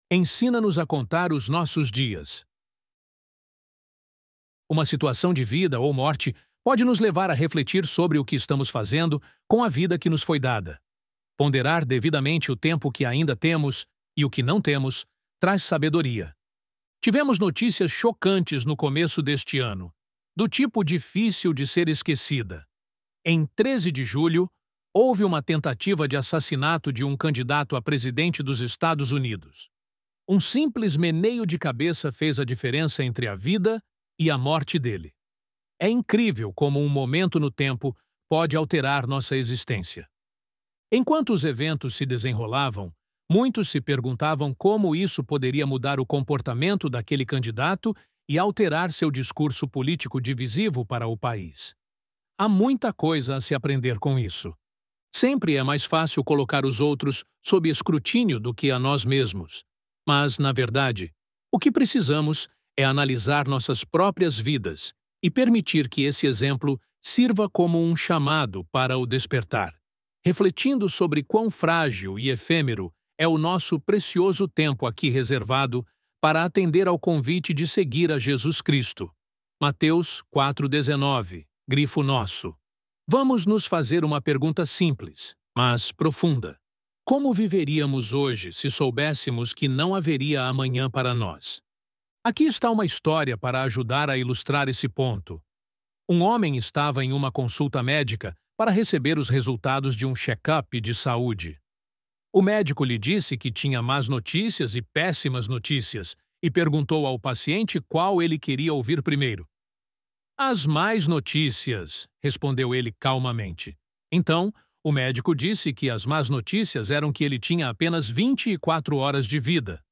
ElevenLabs_“Ensina-Nos_a_Contar_os_Nossos_Dias”.mp3